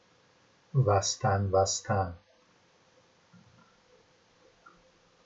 [vastanvastan] n. a kind of game